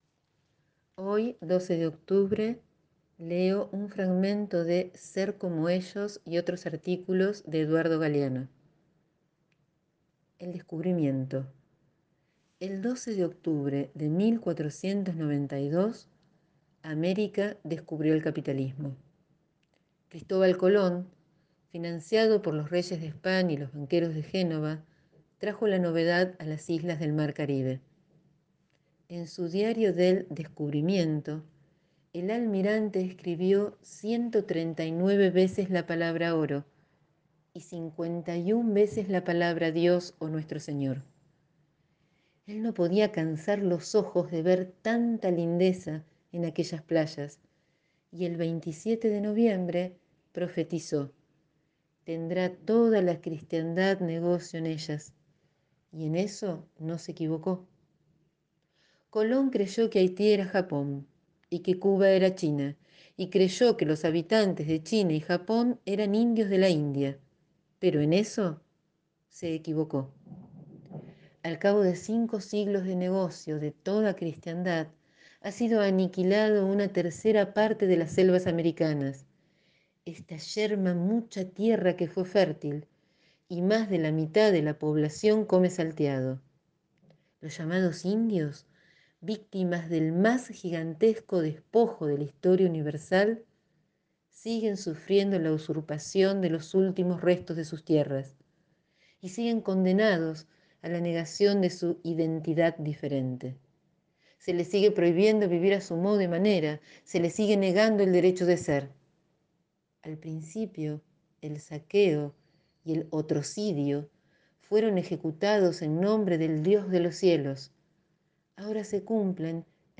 Día de duelo continental, acompaño con la lectura de un fragmento de un texto de Eduardo Galeano.